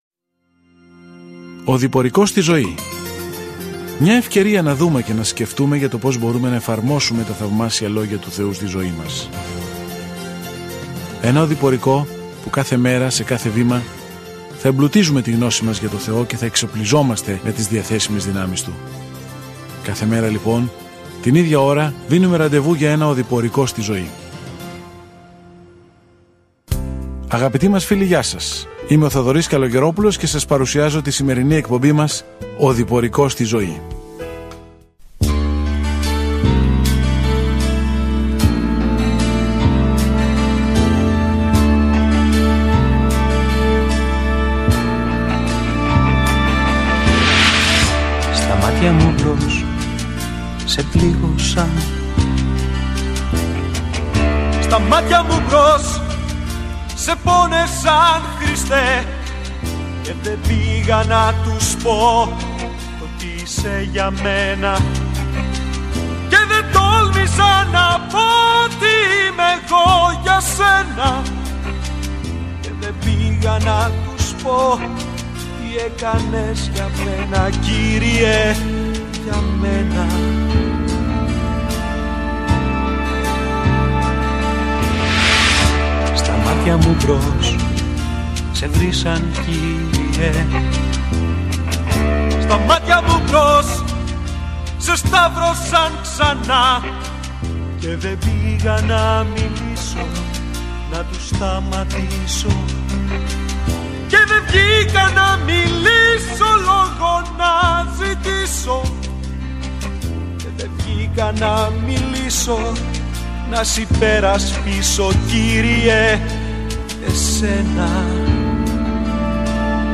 Κείμενο Β΄ ΙΩΑΝΝΟΥ 1:1 Έναρξη αυτού του σχεδίου Ημέρα 2 Σχετικά με αυτό το σχέδιο Αυτή η δεύτερη επιστολή από τον Ιωάννη βοηθά μια γενναιόδωρη γυναίκα και μια τοπική εκκλησία να ξέρουν πώς να εκφράσουν την αγάπη μέσα στα όρια της αλήθειας. Καθημερινά ταξιδεύετε στο 2 Ιωάννη καθώς ακούτε την ηχητική μελέτη και διαβάζετε επιλεγμένους στίχους από το λόγο του Θεού.